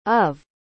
Pronunciación de of
Of normalmente se dice como /əv/, o sea, una «a» cortita seguida de una «v» suave. Si hablas rapidito, puede sonar casi solo como /ə/ (el famoso schwa).